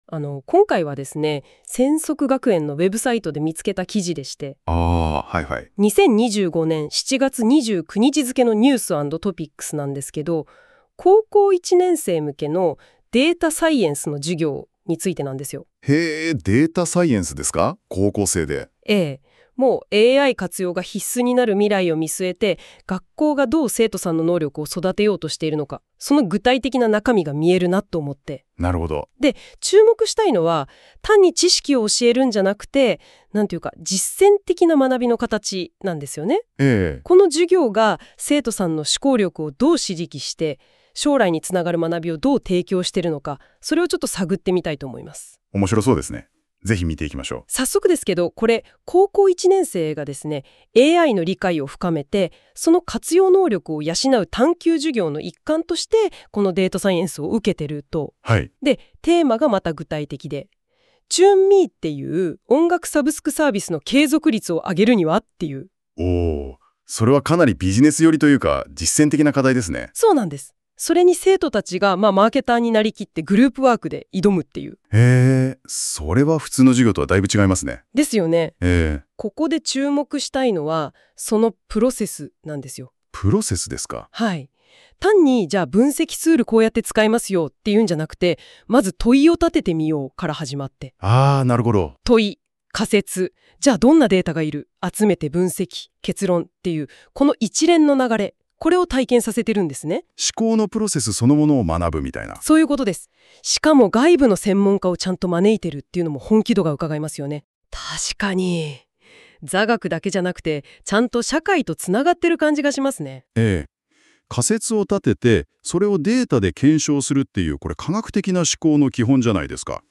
広報でも、今回の研修で得た生成AIの活用の実践として、7月29日のNEWS&TOPICSの記事「高1 データサイエンスに関する探究授業を行いました」の音声解説を生成AIによって自動作成しました。
記事を読み込ませる作業に1分、生成AIによる音声解説の自動作成に5分、合計6分という短時間で作成したものです。